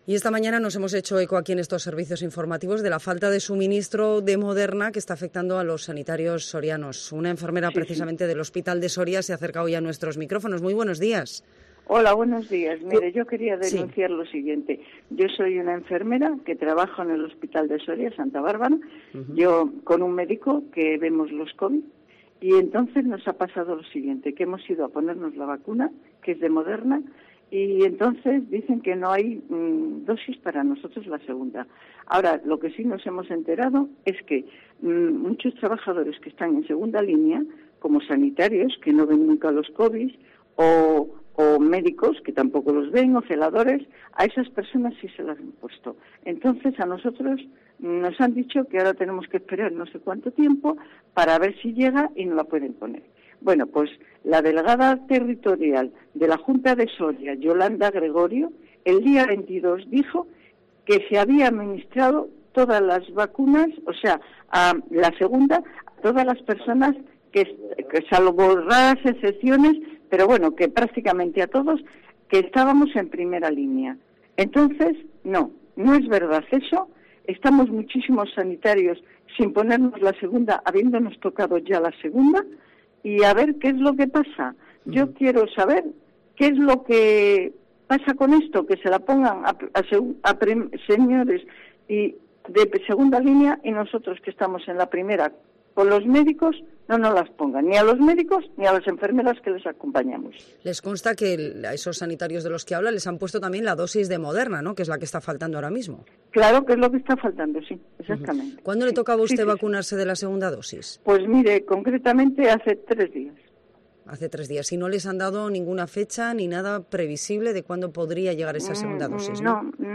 Escucha en Cope Soria a una de las enfermeras de primera línea afectada por la falta de suministro de Moderna